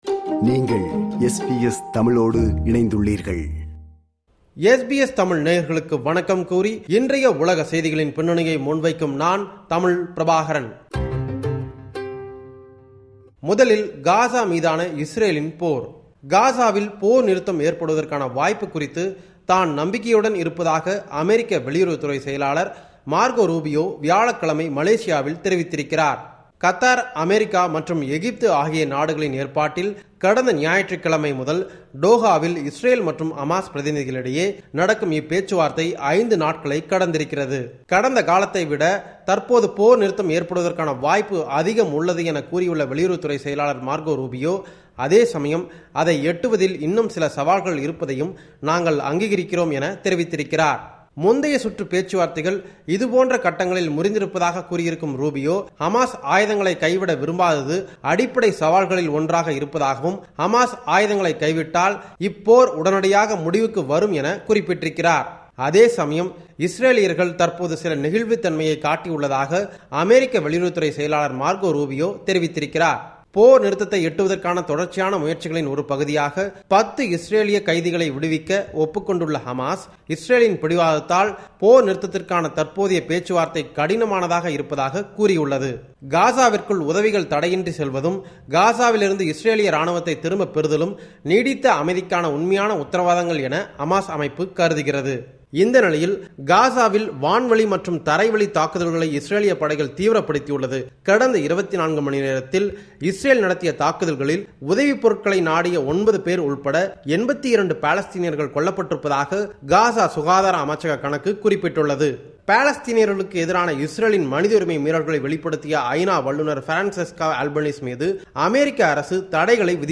இந்த வார உலக செய்திகளின் தொகுப்பு